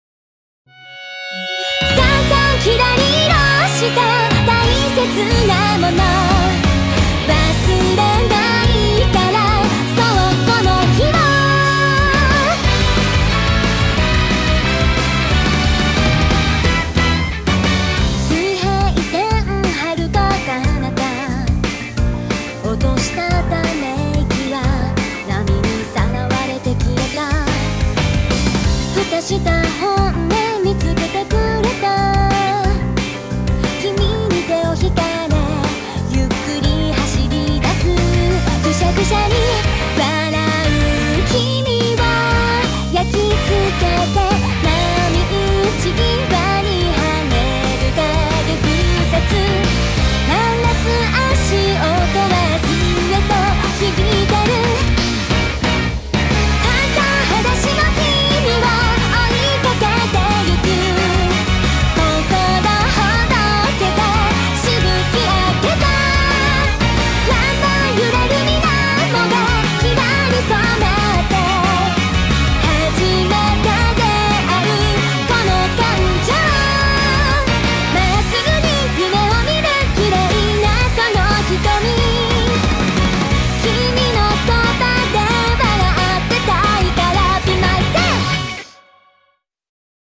BPM180
Audio QualityPerfect (High Quality)